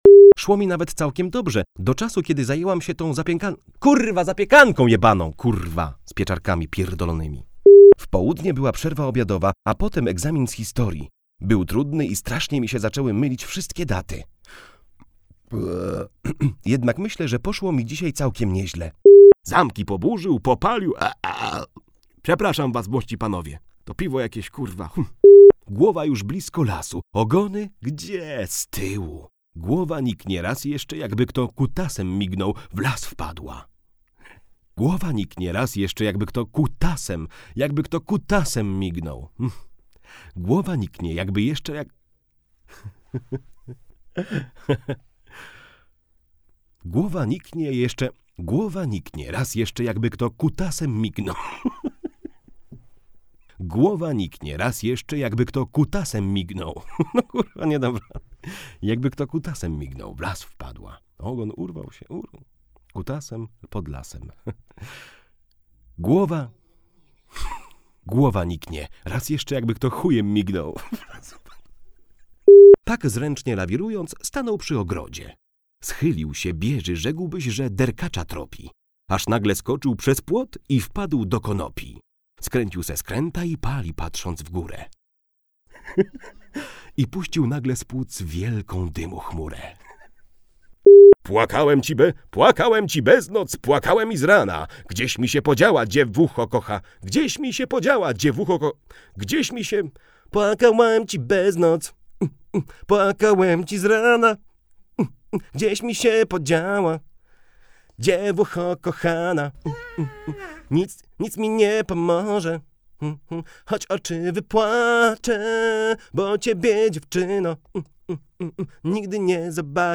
Uwaga!!! Brzydkie wyrazy!
Tutaj możesz posłuchać, jak nie należy nagrywać:
Audiobooki kiksmix